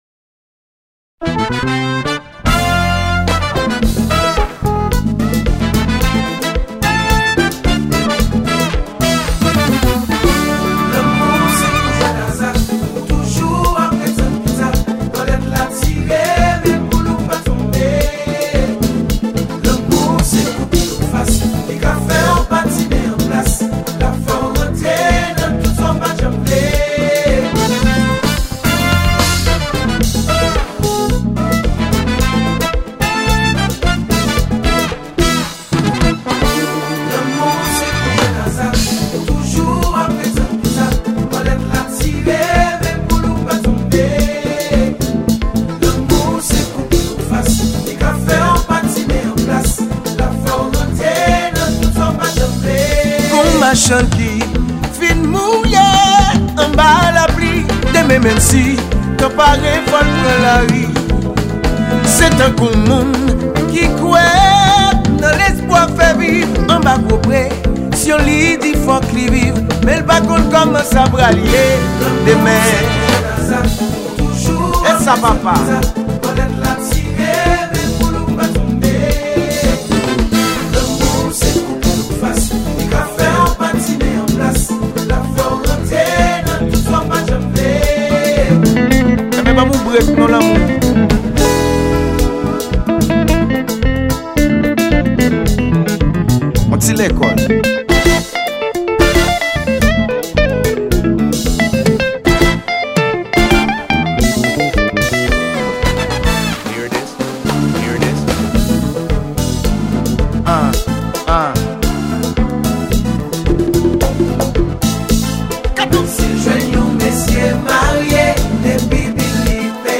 sitou konpa a.
mizik sa ofri nou yon solo  gita ki chaje kalite.